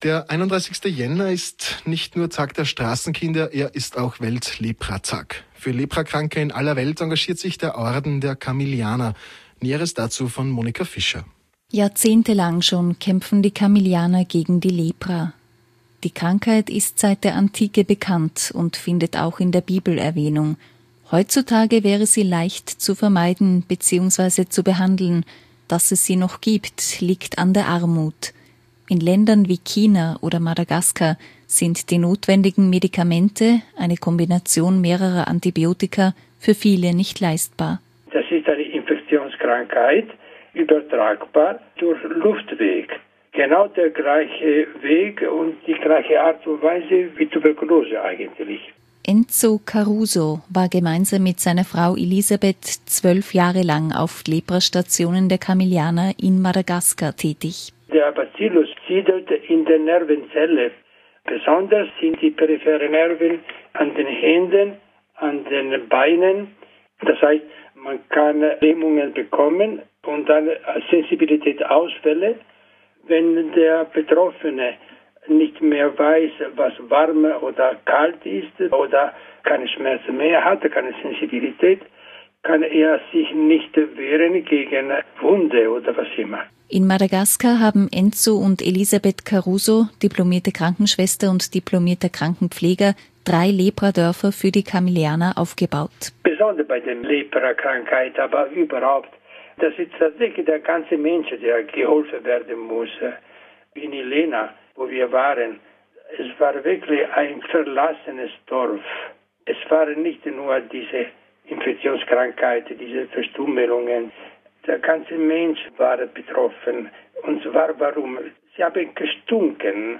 Radiobeitrag von Radio Stephansdom zum Lepraprojekt der Kamillianer Jan. 2010.